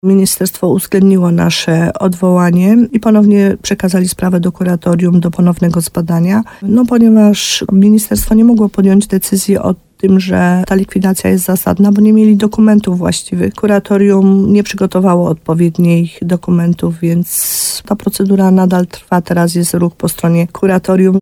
– Chociaż uchwała rady gminy została podjęta, wciąż brakuje zgody MEN-u – mówiła w programie Słowo za Słowo na antenie RDN Nowy Sącz wójt gminy Podegrodzie, Małgorzata Gromala.